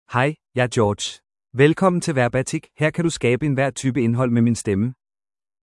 George — Male Danish AI voice
George is a male AI voice for Danish (Denmark).
Voice sample
Listen to George's male Danish voice.
George delivers clear pronunciation with authentic Denmark Danish intonation, making your content sound professionally produced.